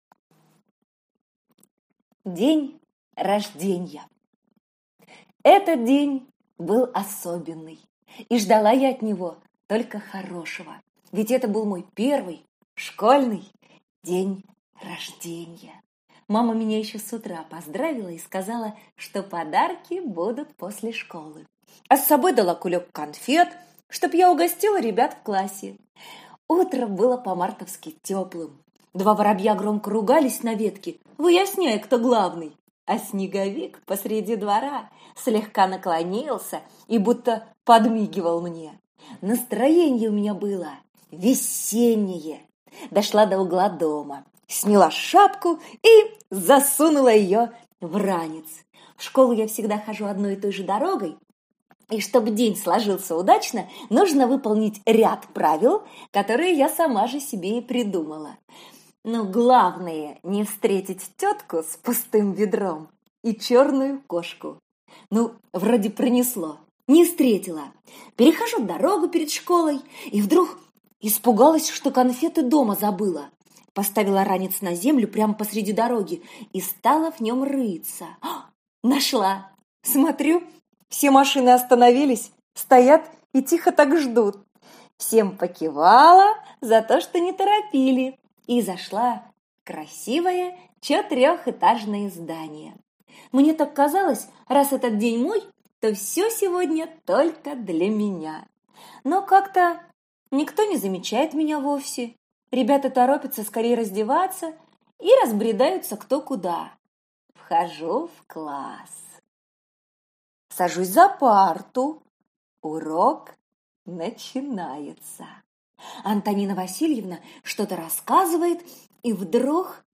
Аудиокнига День Рождения. Истории из жизни одной девочки | Библиотека аудиокниг